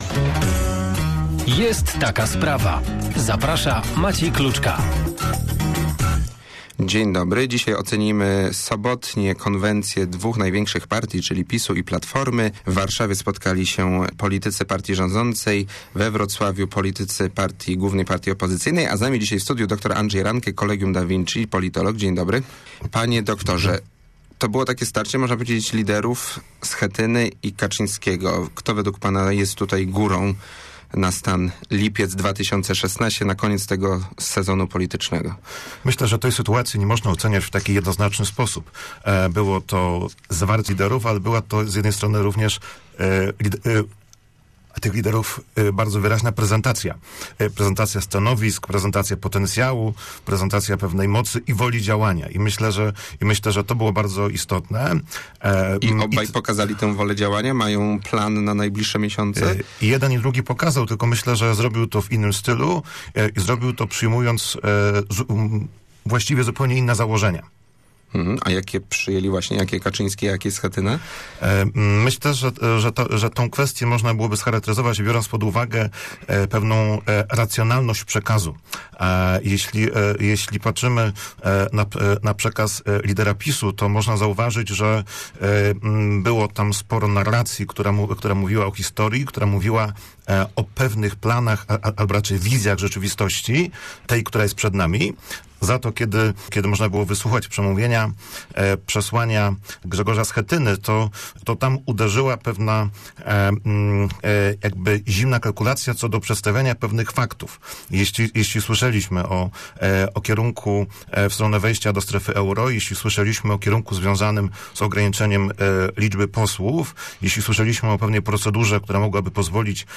(cała rozmowa poniżej)